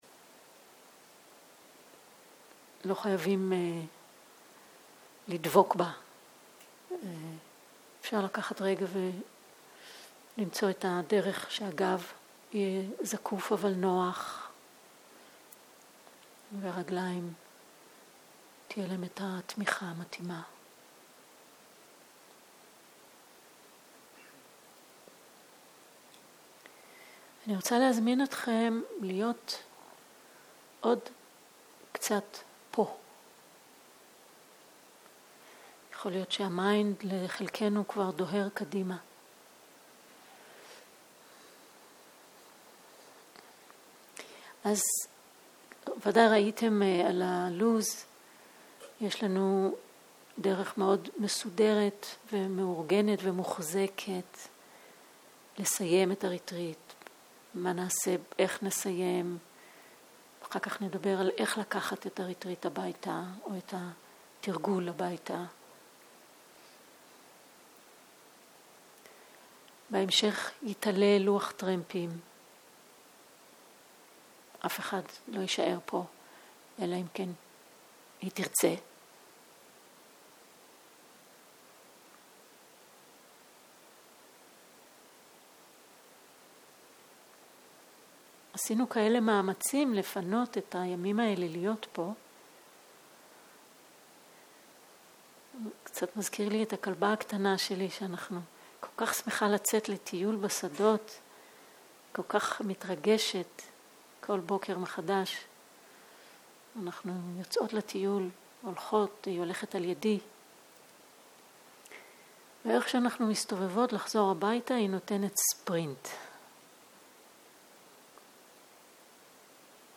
בוקר - הנחיות מדיטציה
סוג ההקלטה: שיחת הנחיות למדיטציה